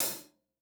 TC Live HiHat 04.wav